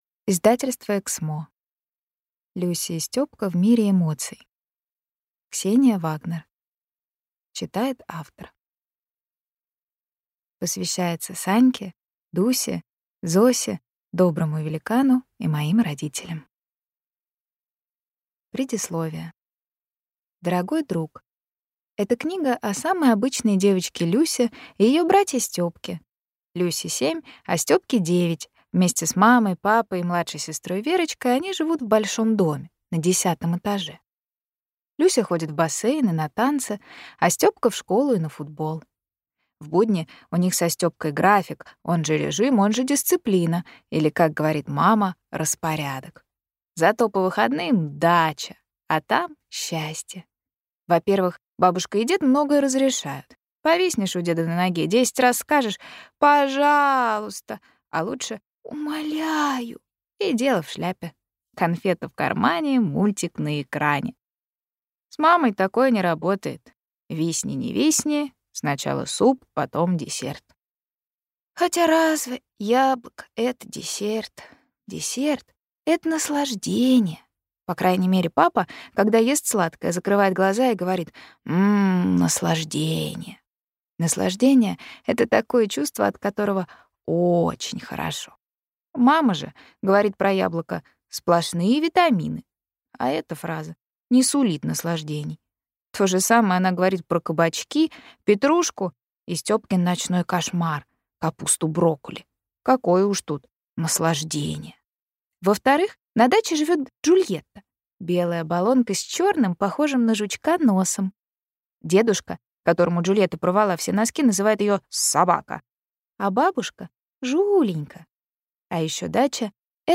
Аудиокнига Люся и Стёпка в мире эмоций. 10 шагов, которые научат ребёнка понимать свои чувства и общаться с другими | Библиотека аудиокниг